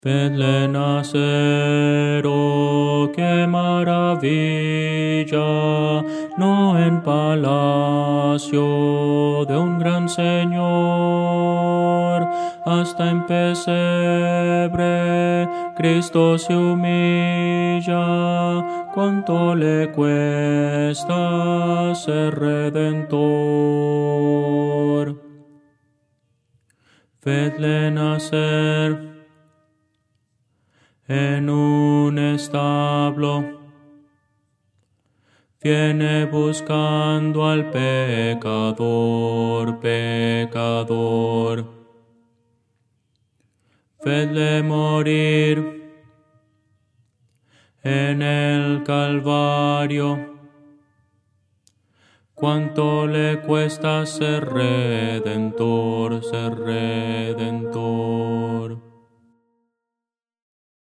Voces para coro
Contralto – Descargar
Audio: MIDI